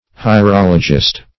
Hierologist \Hi`er*ol"o*gist\